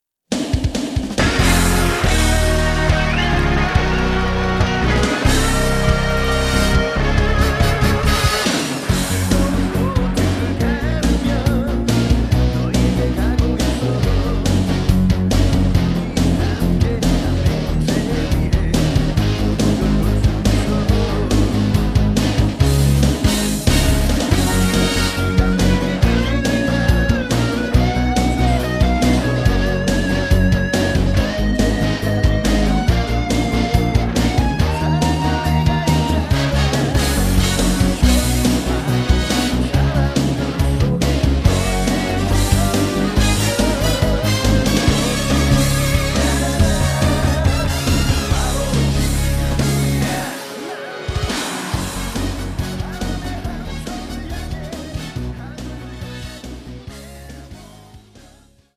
음정 -1키
장르 가요 구분